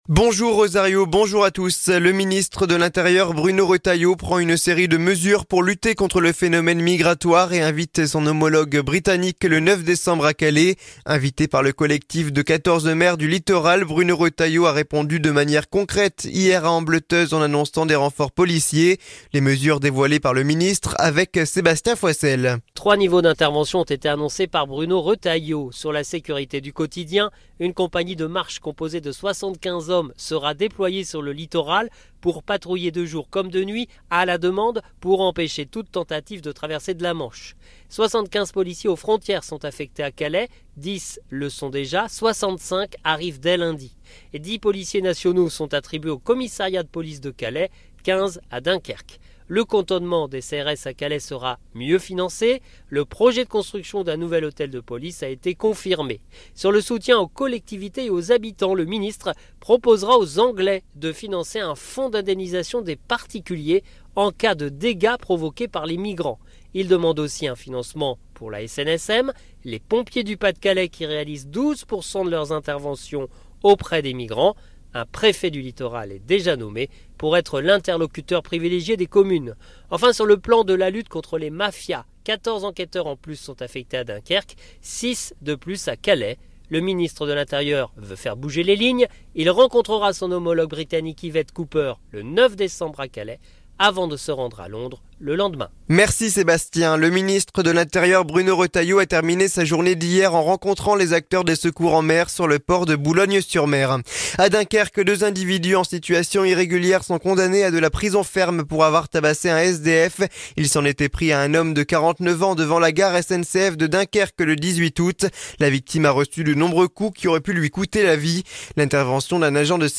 Le journal du samedi 30 novembre 2024